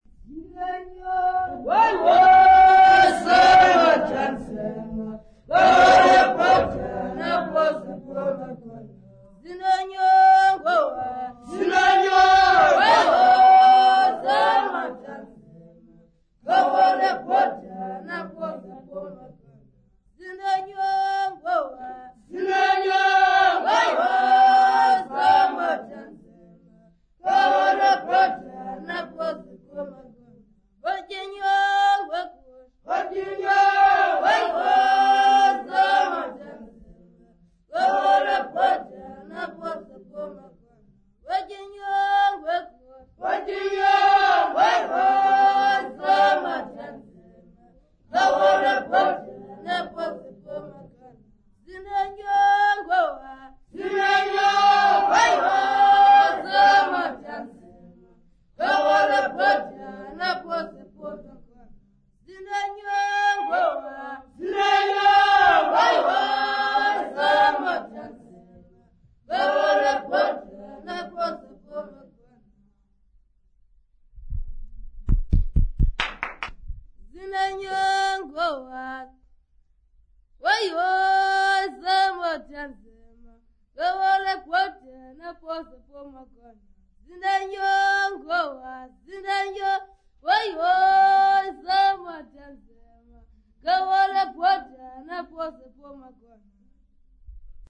Xhosa boys
Folk songs, Xhosa South Africa
Africa South Africa Lumko, Eastern Cape sa
field recordings
Unaccompanied traditional Xhosa song.